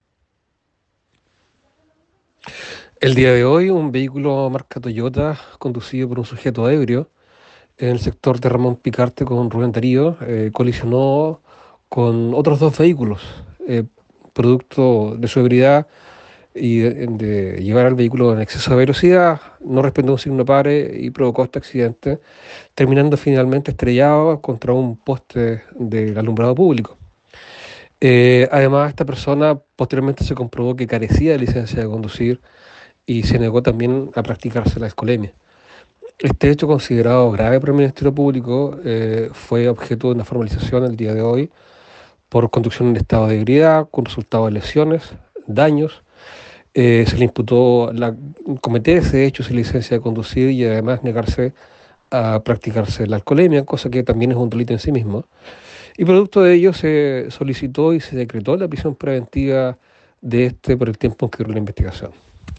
Breves Policial  Valdivia :  El fiscal Carlos Bahamondes  informó sobre un imputado por manejo en estado de ebriedad que causó un accidente con 3 lesionados esta madrugada en Valdivia.
Fiscal Carlos Bahamondes…
FISCAL-CARLOS-BAHAMONDES.m4a